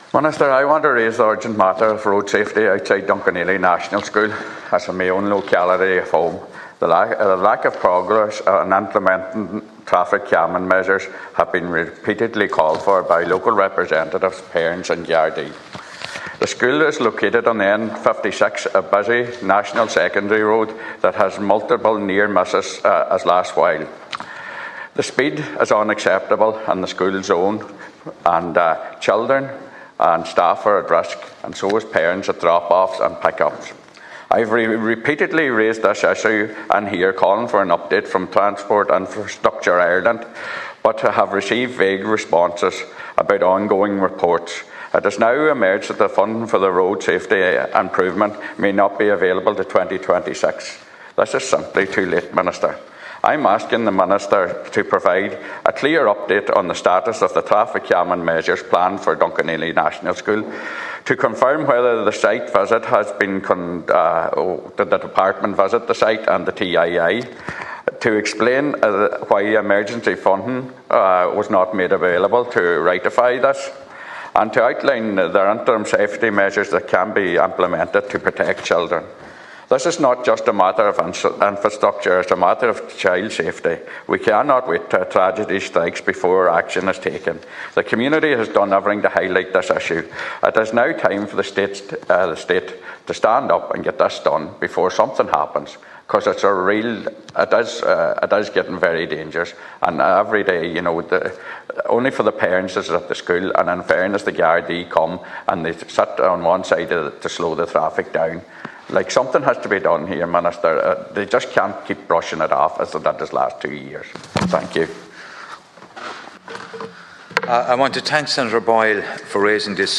The Seanad has been told that Donegal County Council has been given clearance to begin design on a safety scheme for Dunkineely National School near Killybegs.